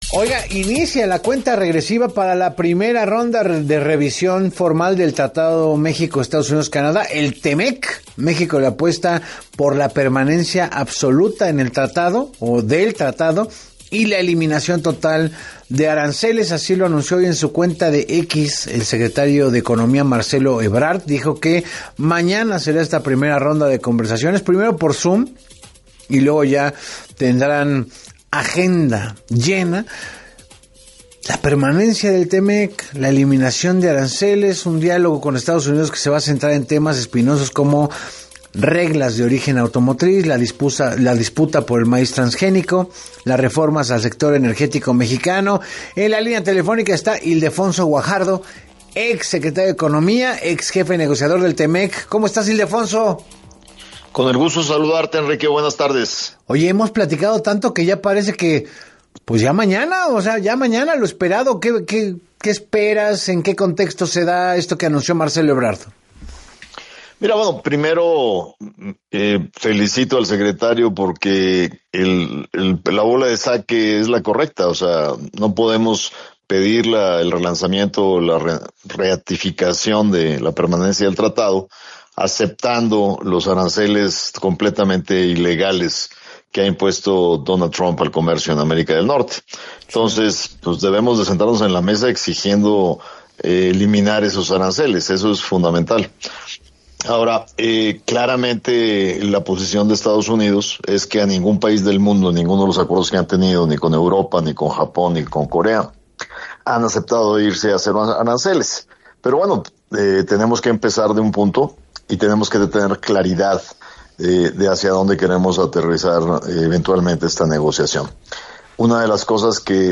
En entrevista para “Así Las Cosas con Enrique Hernández Alcázar”, el especialista subrayó que no se puede aceptar la permanencia de aranceles “completamente ilegales” y que han sido impuestos de manera unilateral por la administración del presidente Donald Trump, afectando la estabilidad comercial en América del Norte.